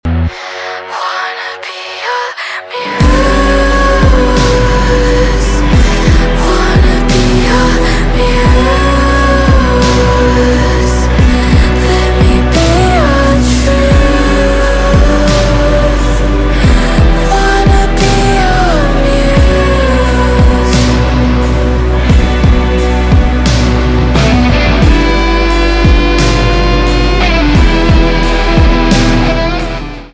поп
гитара
нежные
спокойные